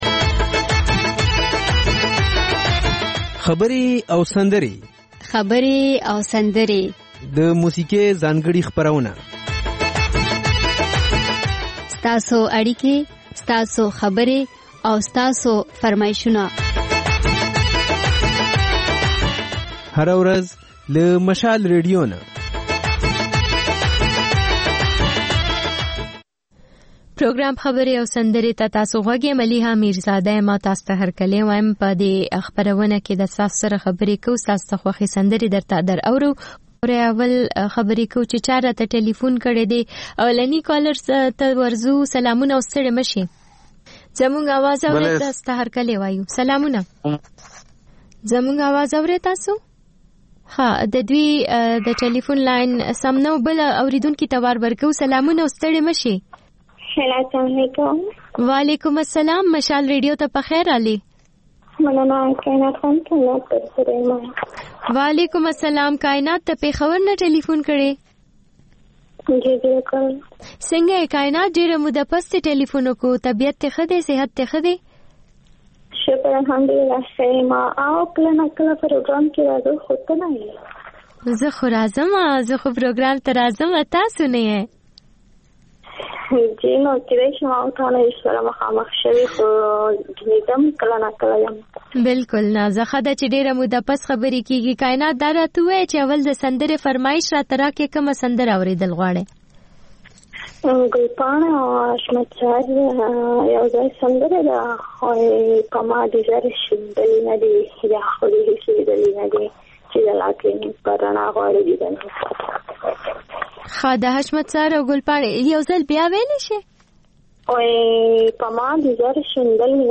دلته د خبرې او سندرې خپرونې تکرار اورئ. په دې خپرونه کې له اورېدونکو سره خبرې کېږي، د هغوی پیغامونه خپرېږي او د هغوی د سندرو فرمایشونه پوره کېږي.